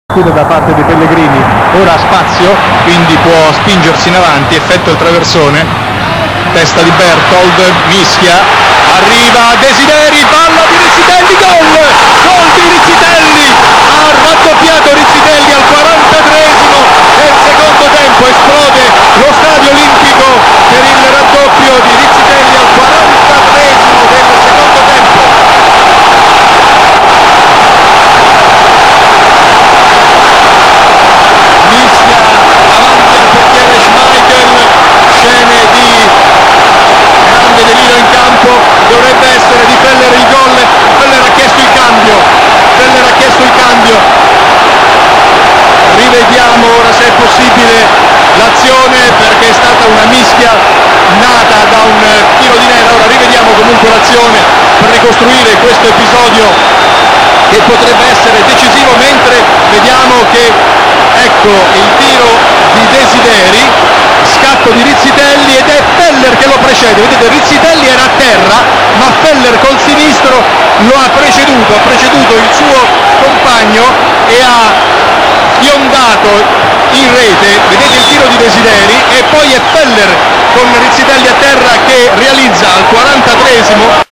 roma broendby 90-91 rudi da raidue commento giorgio martino.wma